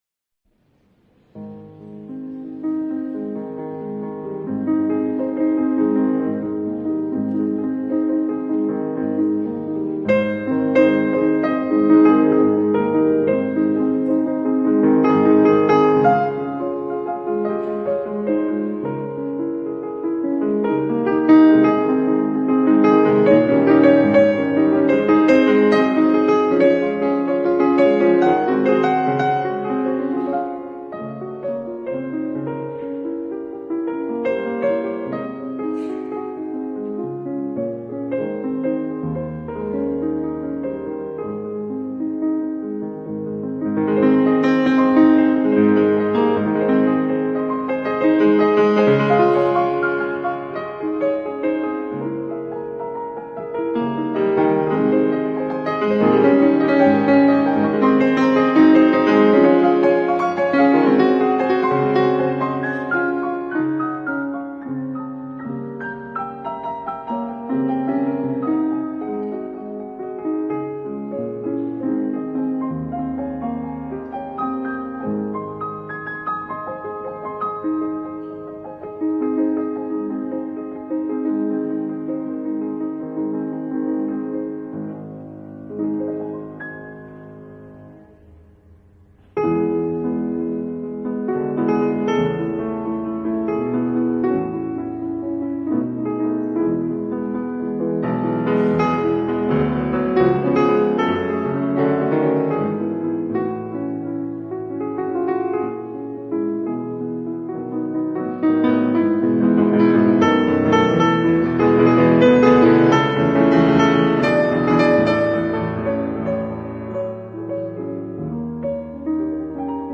reworked it for a wonderful piano solo